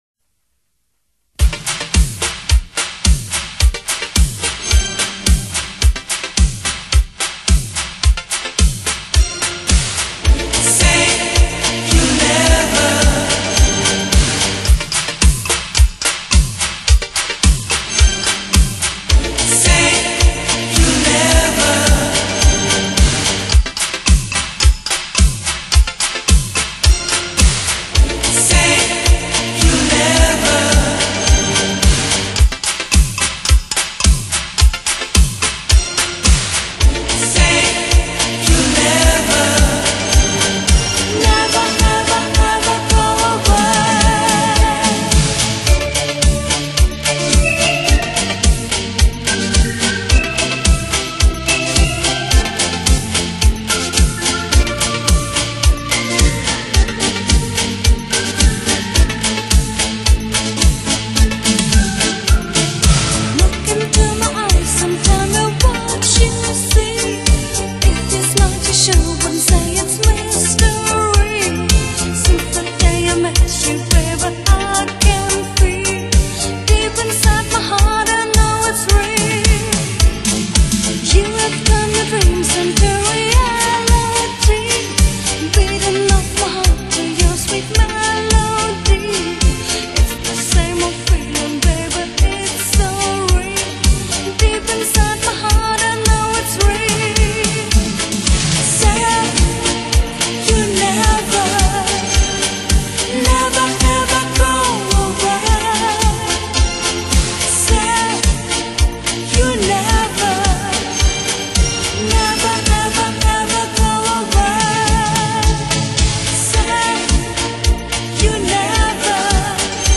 介绍： Italo disco